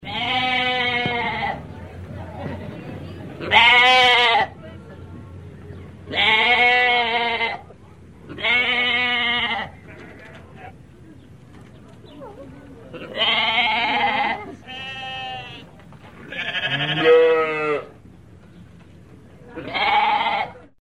Звуки овец, баранов
На этой странице собраны натуральные звуки овец и баранов: от тихого блеяния до громкого крика.
Звуки барана блеют по своему